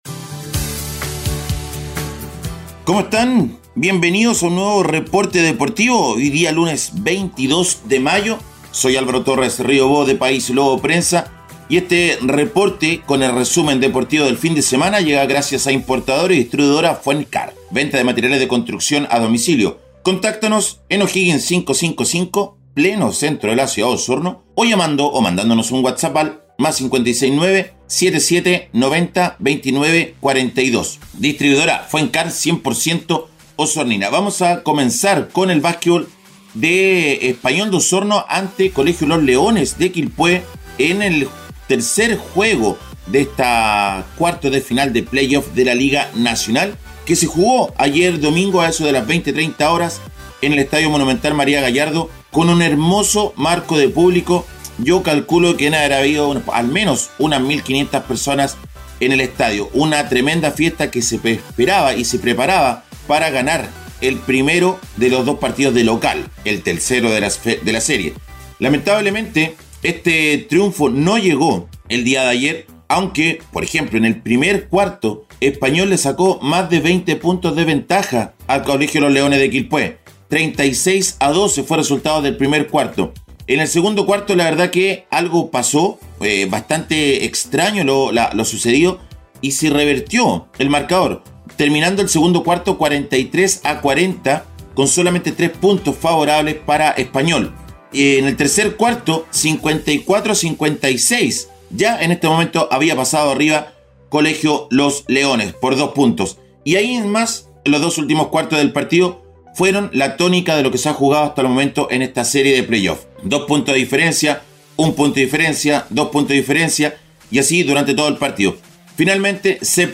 Playoff: hablan los DTs de los equipos.